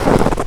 STEPS Snow, Walk 06-dithered.wav